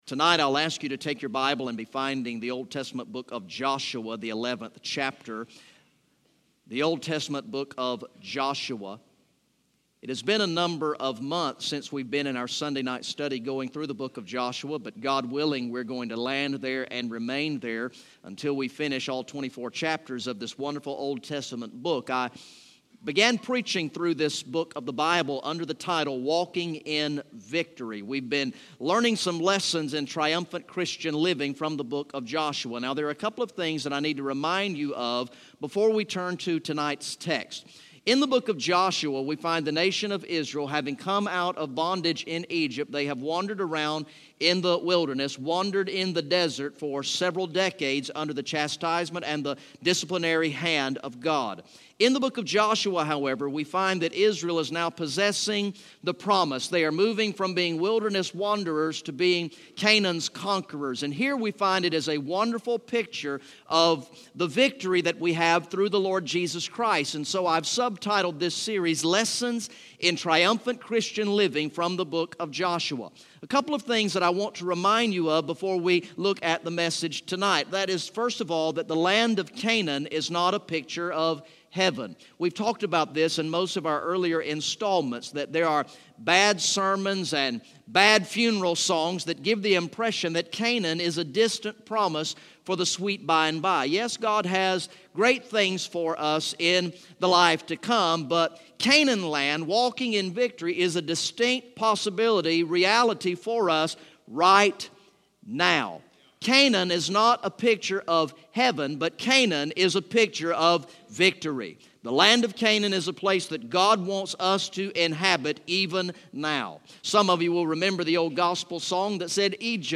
Message #14 from the sermon series through the book of Joshua entitled "Walking in Victory" Recorded in the evening worship service on Sunday, April 24, 2016